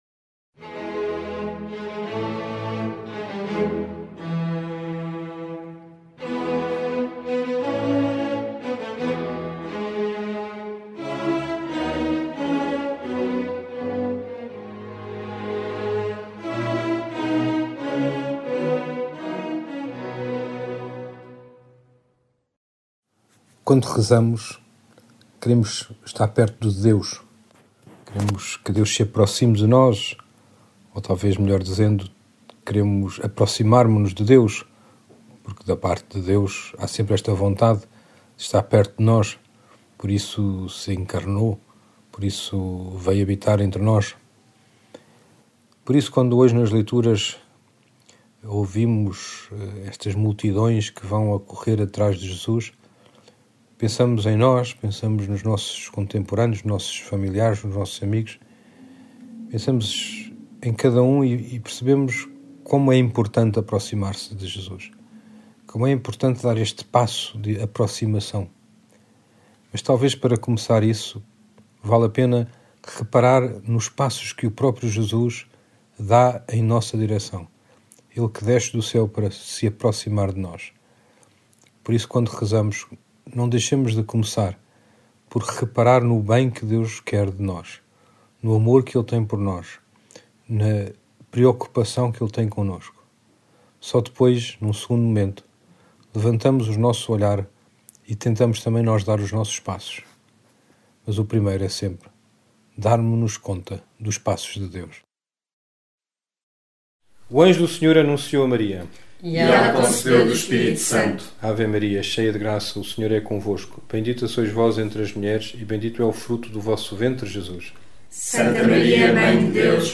Angelus, Meditações Diárias